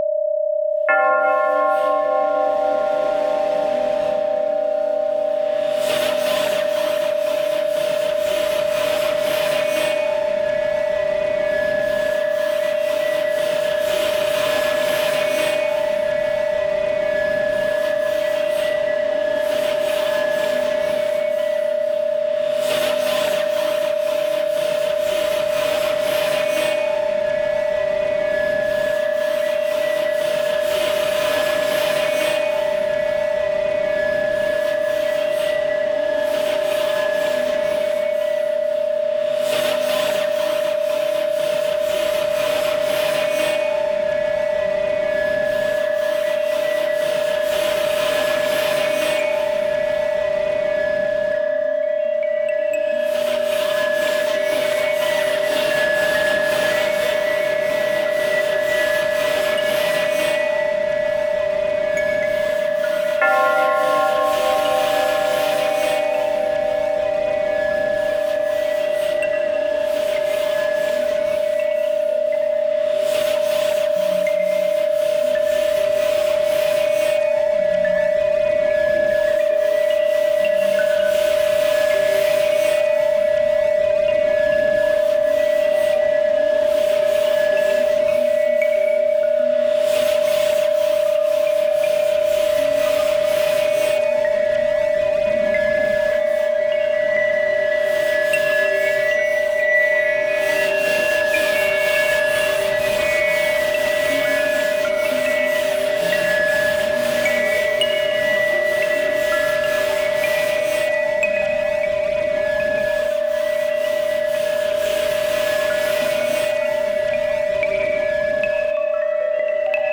ある種のミニマル・アンビエント系の様相でありながら、総時間189分（3x3x3x3x7）をかけて、
更にこれら42曲は、特別な音響的デザインにより、ディープなバイノーラル・ビーツ体験をもたらす。
バイノーラル・ビーツの為の正弦波と、教会の鐘の音のサンプリングを除き、
総ての音はギター起源で録音されている。